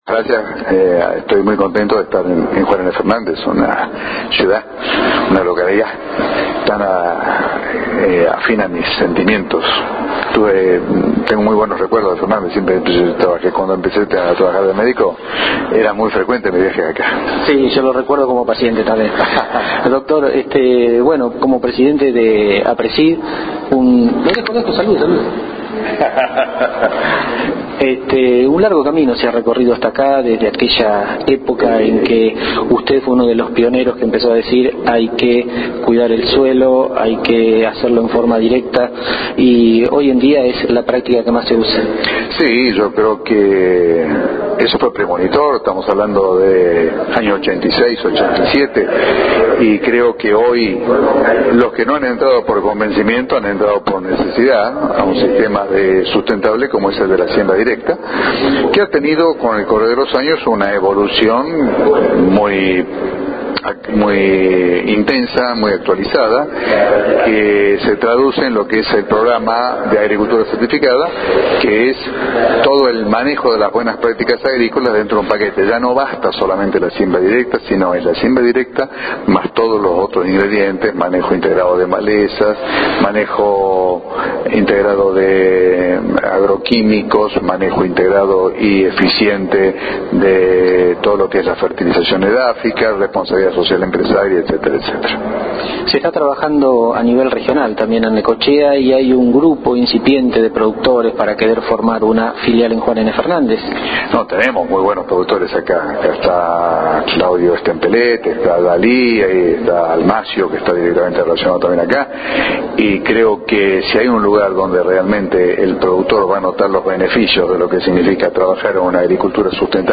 Antes de comenzar la charla/debate tuvimos el privilegio de conversar unos minutos